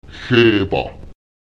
áw [Qù] wird wie das ä in Äther artikuliert.
Lautsprecher háwba [ÈCQùba] der Arm